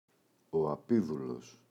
απήδουλος, ο [a’piðulos]